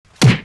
punch2_1.aac